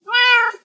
cat_meow2.ogg